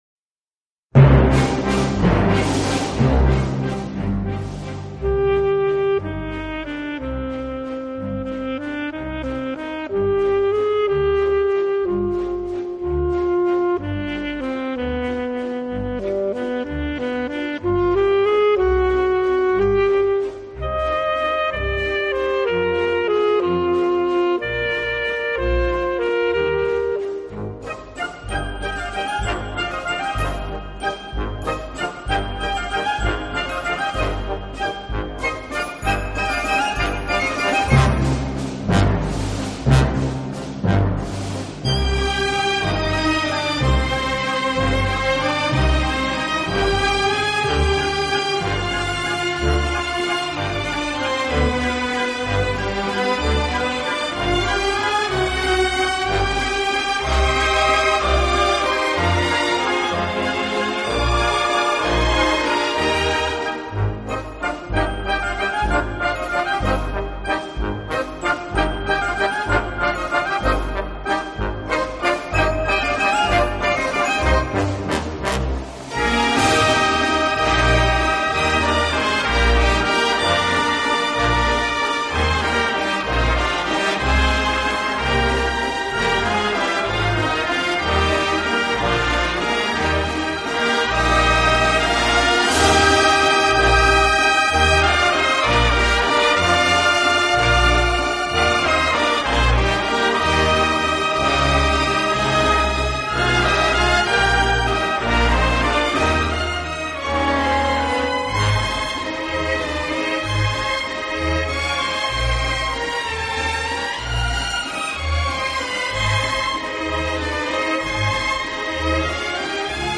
Genre: Classical
的演出方式，自己边拉小提琴边指挥乐队。